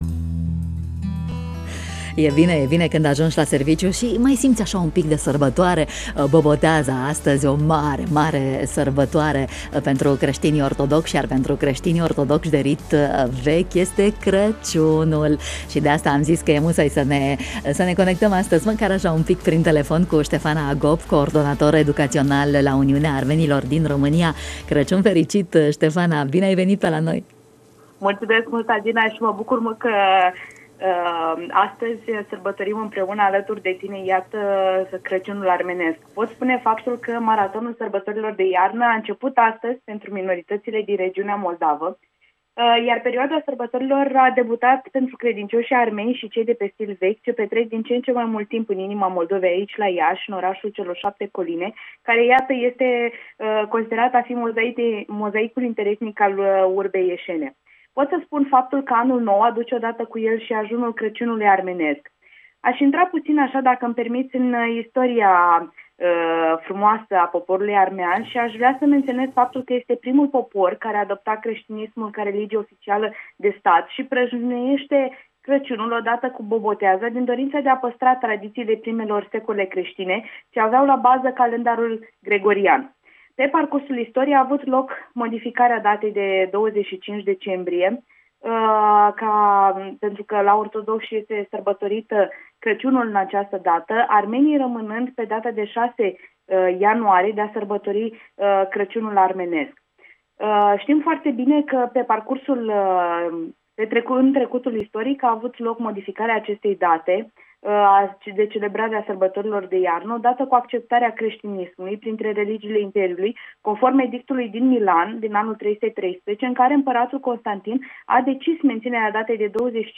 în matinalul Radio România Iaşi.